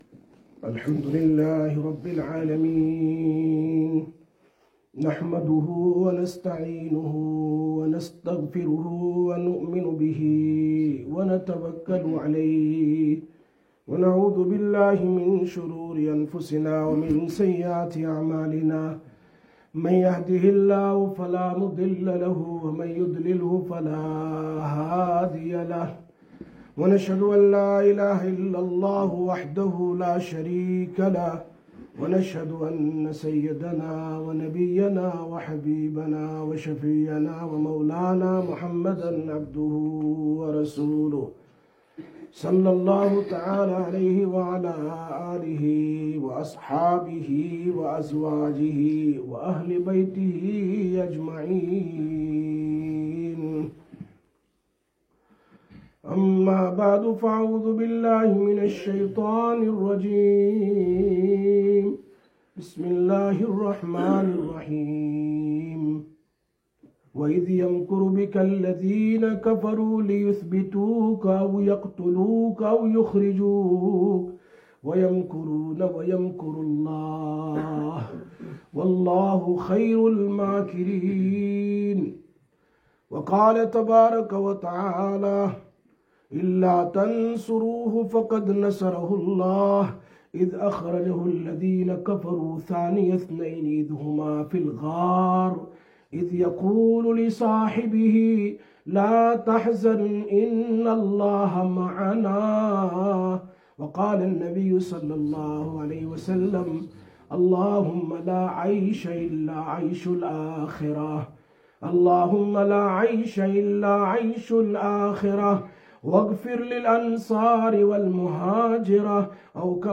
21/07/2023 Jumma Bayan, Masjid Quba